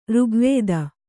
♪ rugvēda